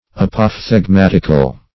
Search Result for " apophthegmatical" : The Collaborative International Dictionary of English v.0.48: Apophthegmatic \Ap`oph*theg*mat"ic\, Apophthegmatical \Ap`oph*theg*mat"ic*al\, a. Same as Apothegmatic .